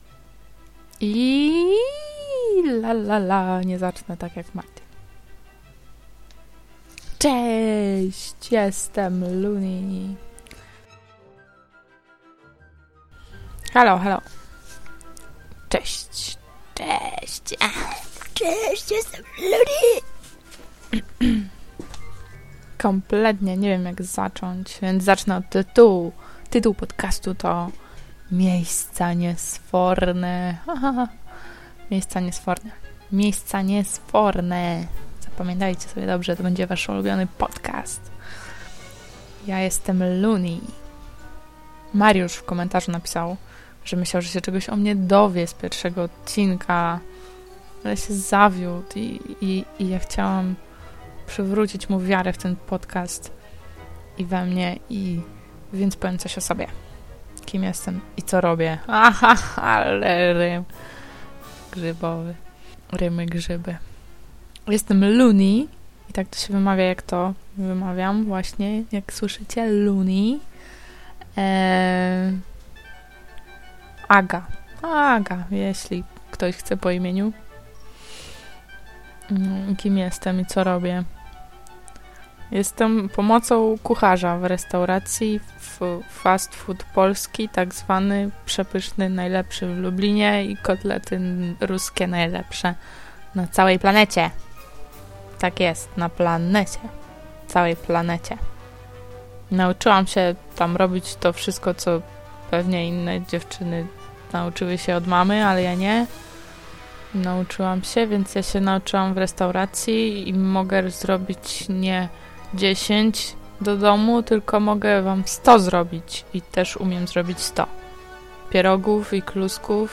Podobał mi się podkład. I masz ładny głos.